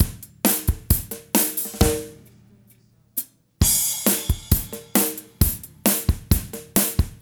13 rhdrm133stop.wav